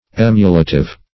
Search Result for " emulative" : The Collaborative International Dictionary of English v.0.48: Emulative \Em"u*la*tive\, a. Inclined to emulation; aspiring to competition; rivaling; as, an emulative person or effort.
emulative.mp3